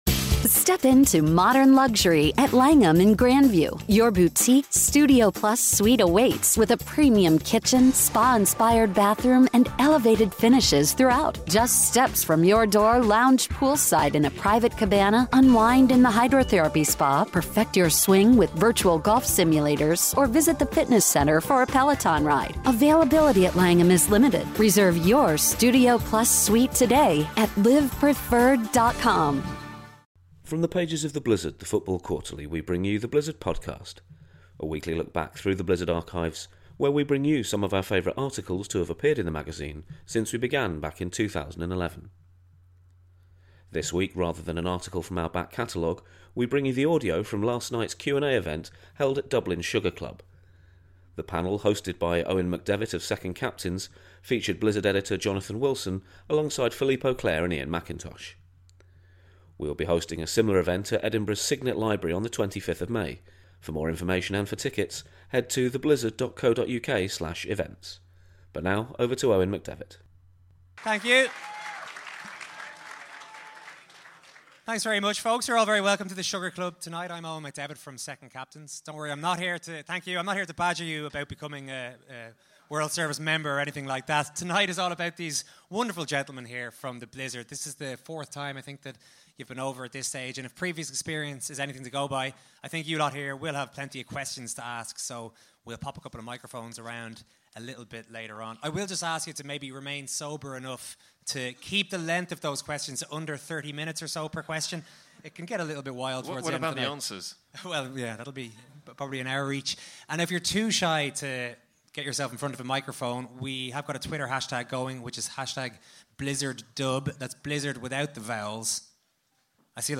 The first half of our Q&A event at Dublin’s Sugar Club held on Monday 24th April.